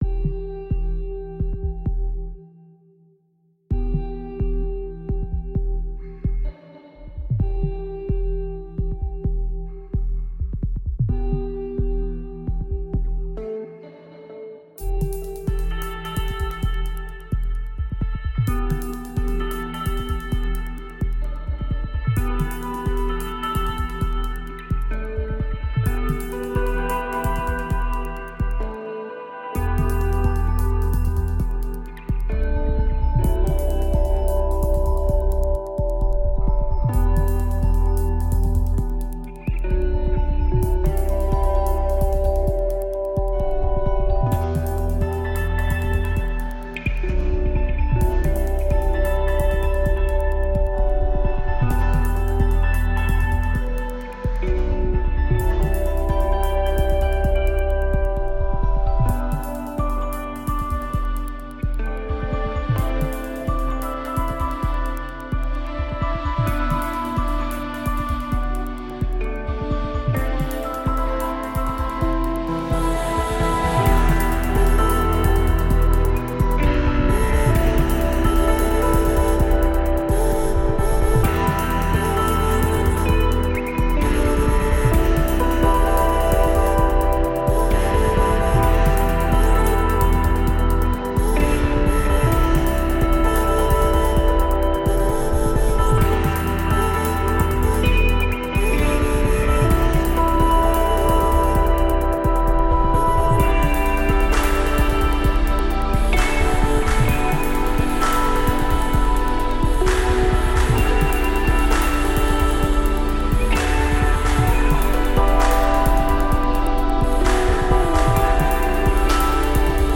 Diverse, intelligent electronica from london.
Tagged as: Electronica, Experimental, Jazz, Ethereal